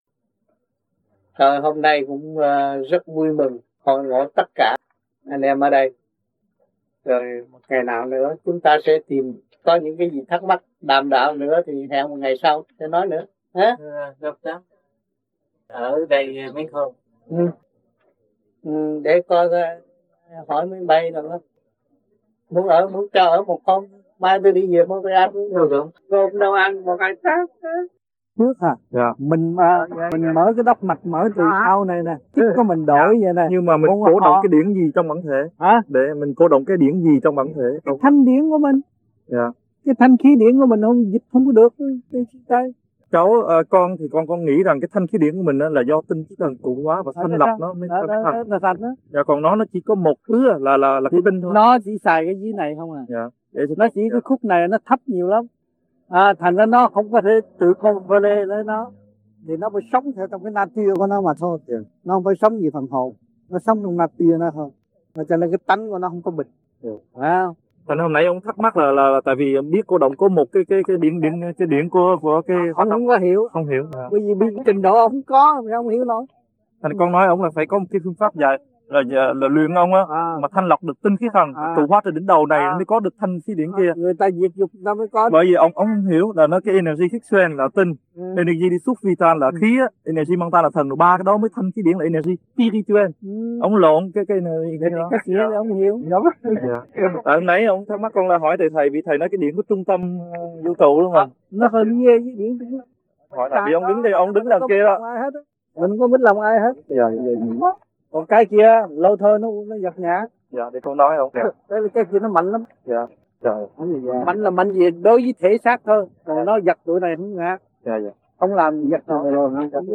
THUYẾT GIẢNG
VẤN ĐẠO